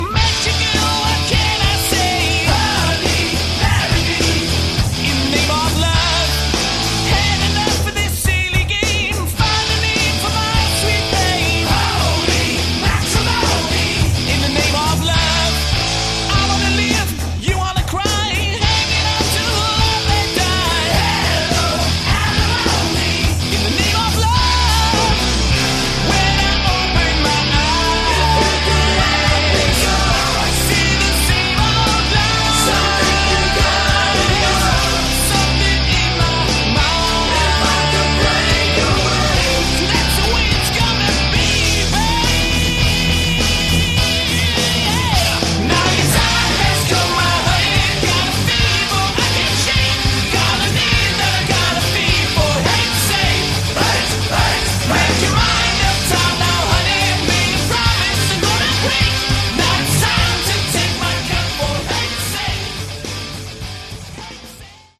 Category: Glam
Vocals
Bass
Guitar
Drums
Keyboards